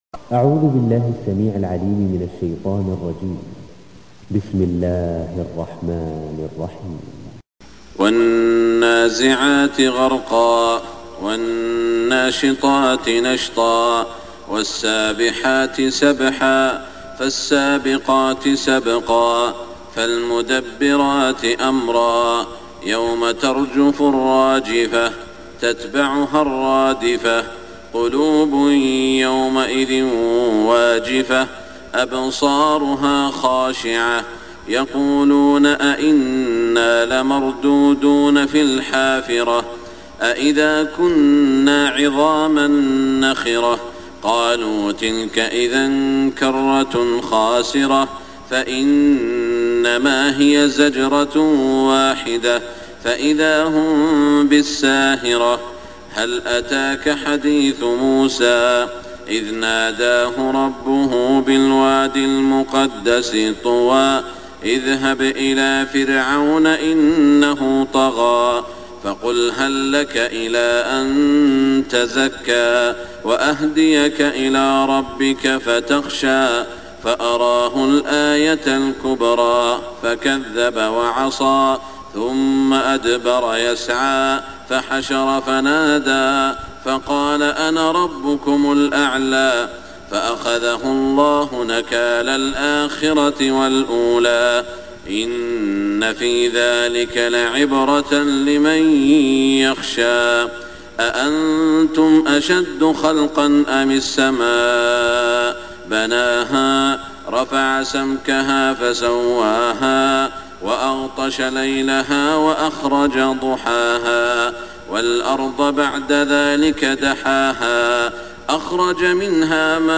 صلاة الفجر 1425 من سورة النازعات > 1425 🕋 > الفروض - تلاوات الحرمين